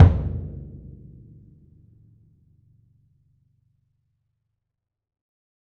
BDrumNewhit_v7_rr2_Sum.wav